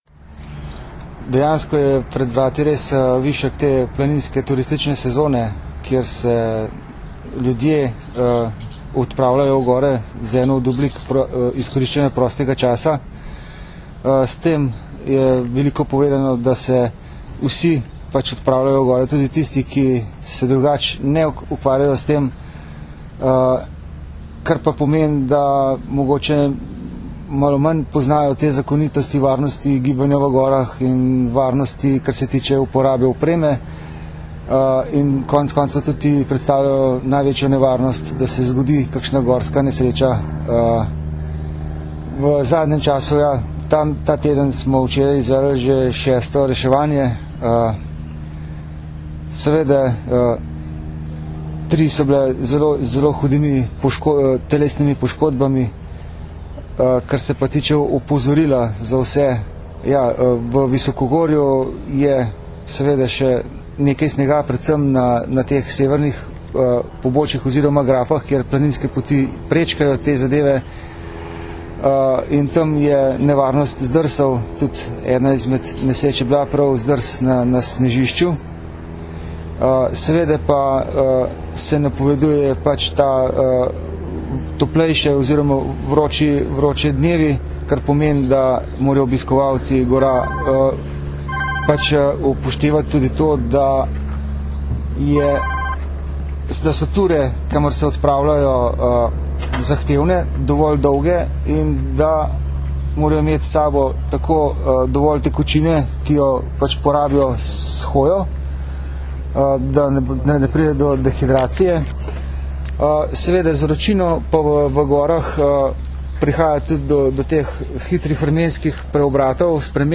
Zvočni posnetek izjave